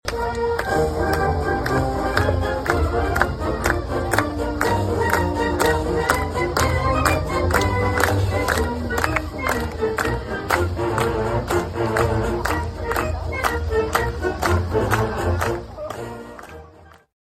Emporia’s annual fireworks show drew a big crowd to the Emporia State campus, and the crowd may have been a bit bigger than normal because the Emporia Municipal Band brought its weekly concert from Fremont Park to Welch Stadium.
2270-music-2.mp3